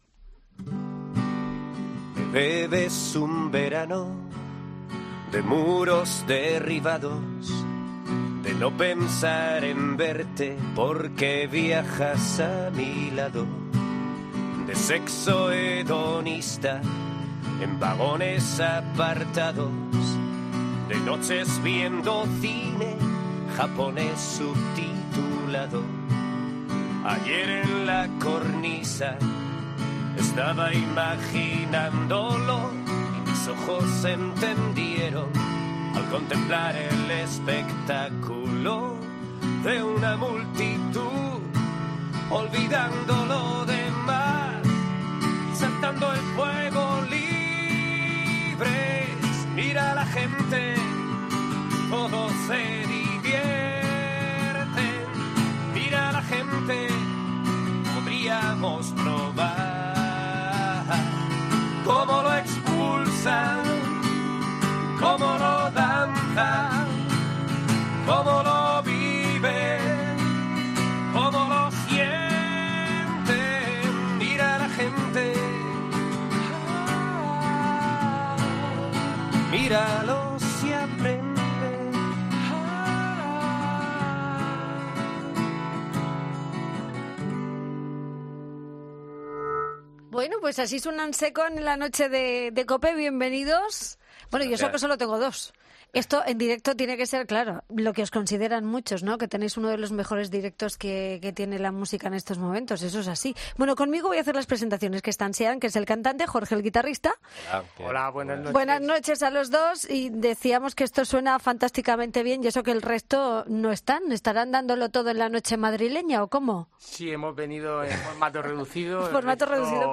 Entrevistas Musicales